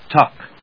/tˈʌk(米国英語)/